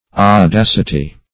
Audacity \Au*dac"i*ty\, n.